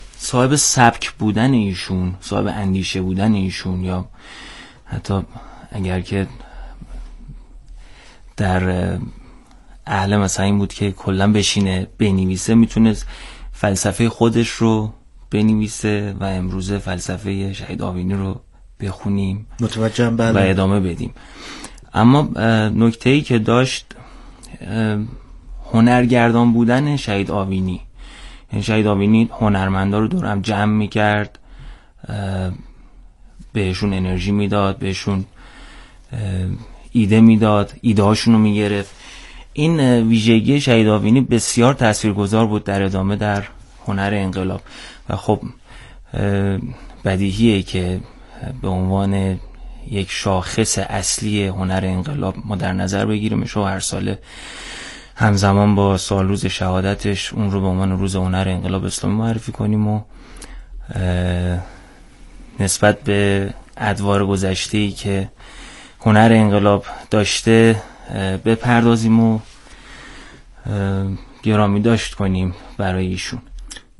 میزگرد تعاملی ایکنا